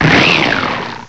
binacle.aif